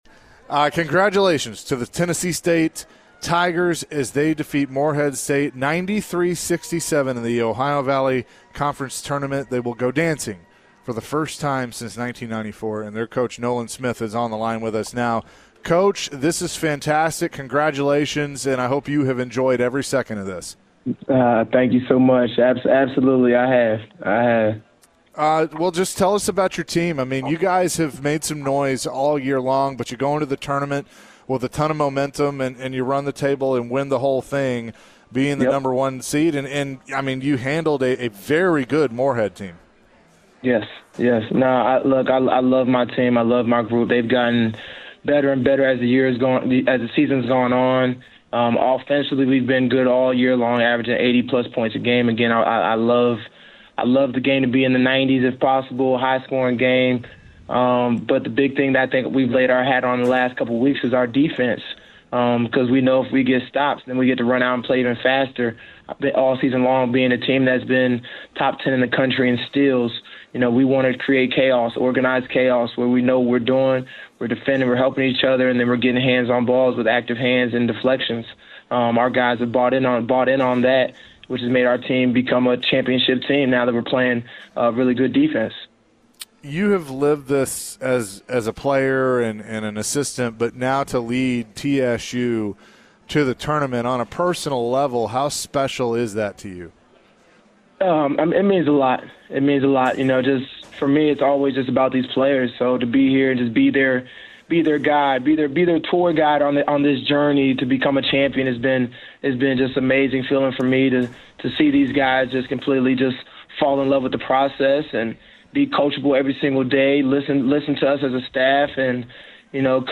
Tennessee State men's basketball head coach Nolan Smith joins the show to discuss the team's OVC championship win and punching its ticket to the NCAA Tournament.